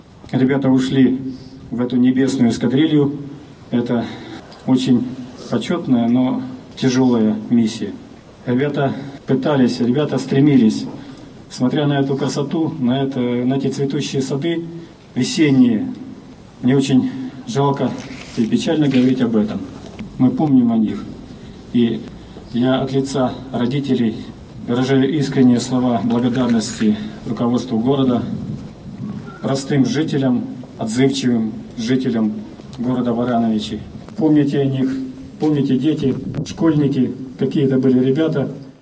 В Барановичах прошел митинг в память о погибших летчиках